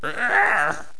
khanat-sounds-sources/_stock/sound_library/voices/voice_attack/carpet_attack5.wav at b47298e59bc2d07382d075ea6095eeaaa149284c
carpet_attack5.wav